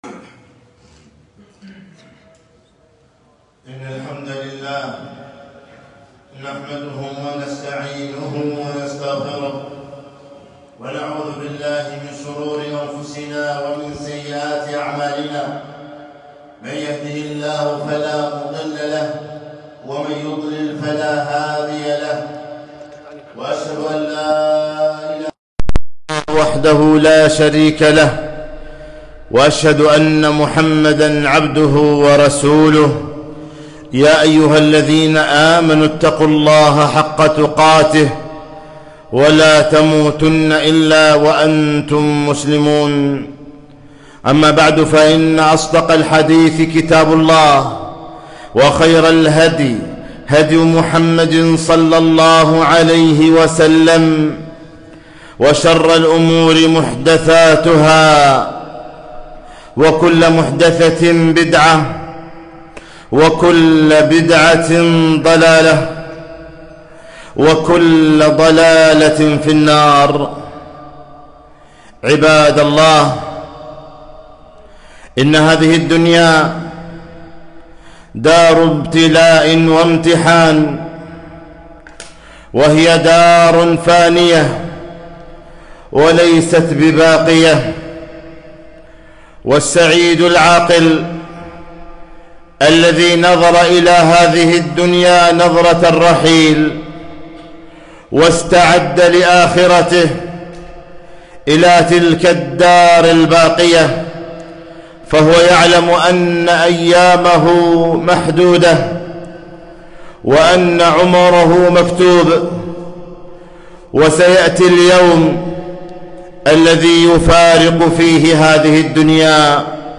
خطبة - ( كل نفس ذائقة الموت)